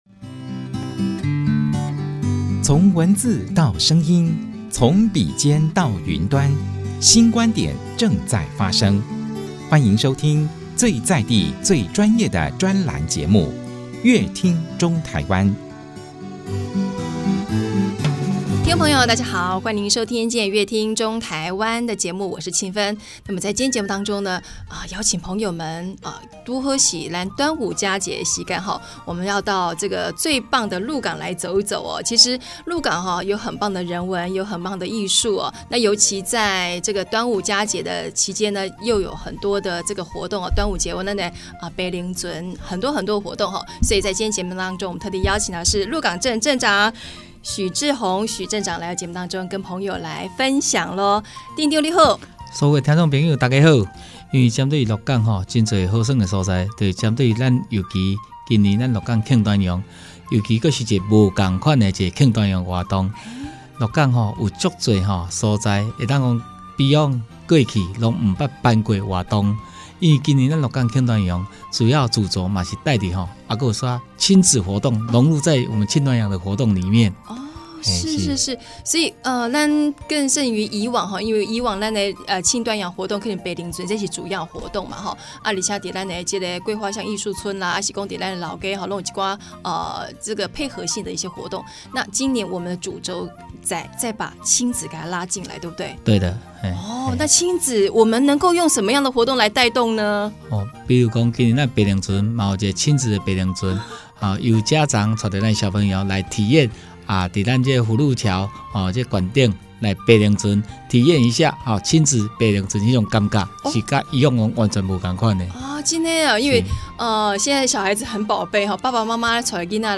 本集來賓：鹿港鎮公所許志宏鎮長 本集主題：「光影饗宴豐富鹿港慶端陽活動」 本集內容： 五月五慶端午，就是要來中部唯一有龍舟賽的鹿港體驗端陽風情，要怎麼玩、怎麼看，才能深入了解鹿港慶端陽的活動呢?所以在今天節目中特別邀請對鹿港最了解的大家長許志宏鎮長來和大家分享今年鹿港慶端陽系列活動。